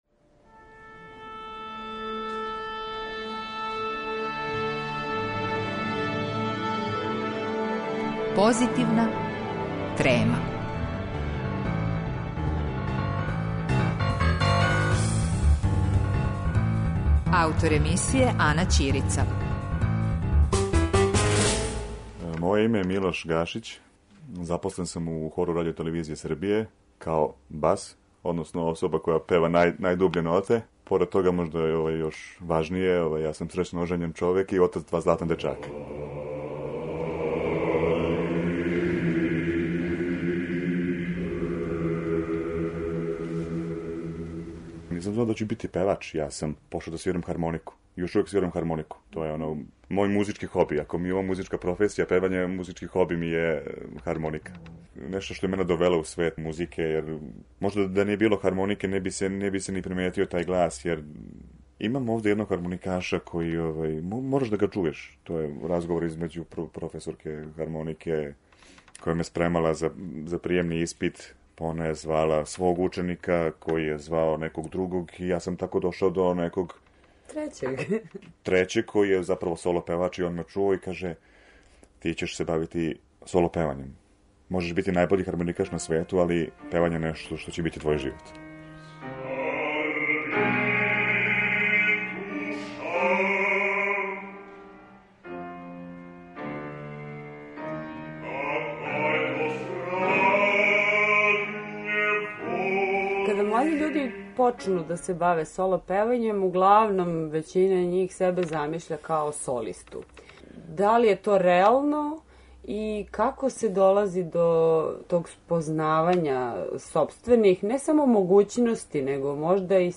Разговор са вокалним уметником